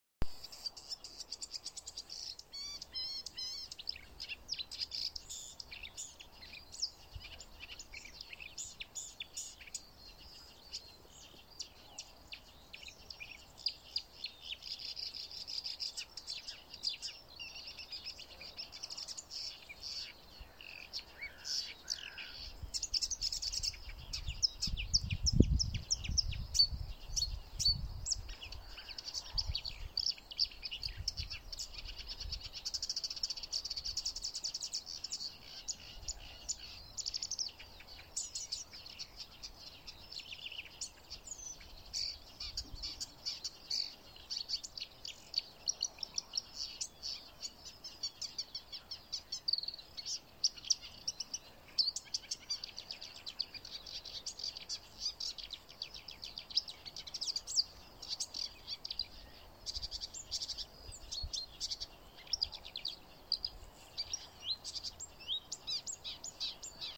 болотная камышевка, Acrocephalus palustris
Administratīvā teritorijaOlaines novads
СтатусПоёт